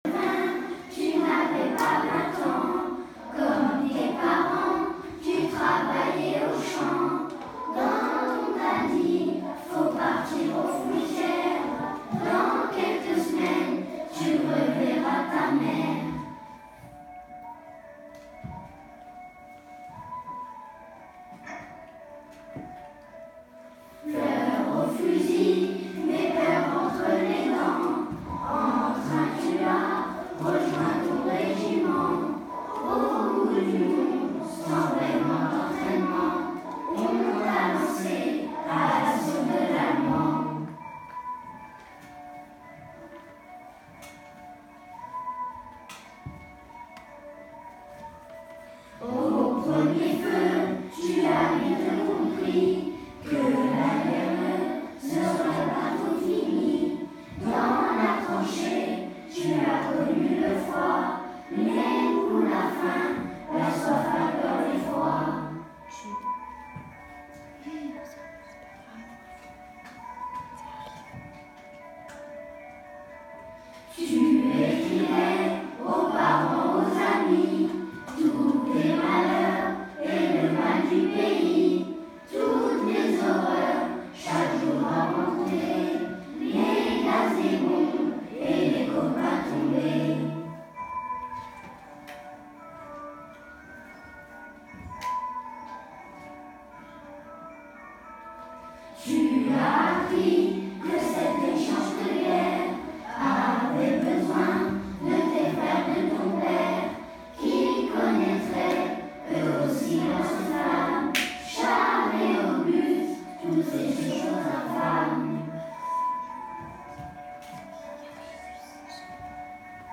Chant : Lettre à un poilu.
Le lundi 8 octobre, nous avons commencé à apprendre le second chant.